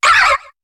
Cri de Carabing dans Pokémon HOME.